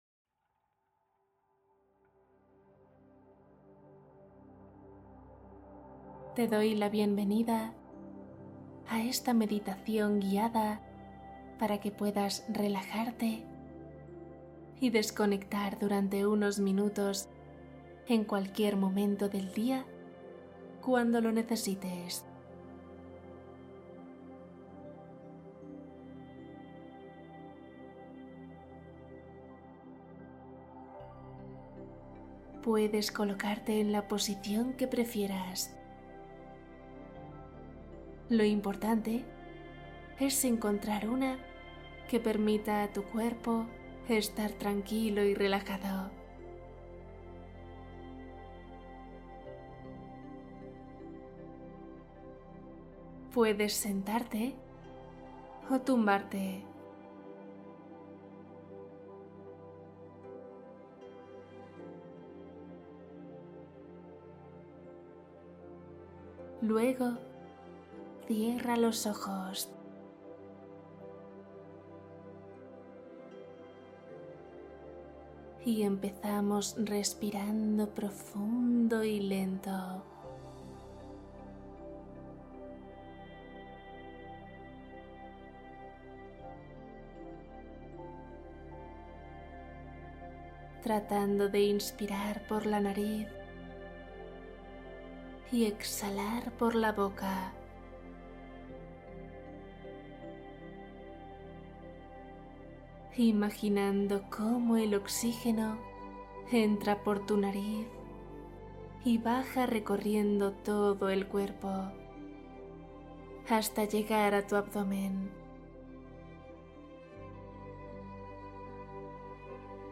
Una meditación breve adaptable a cualquier momento del día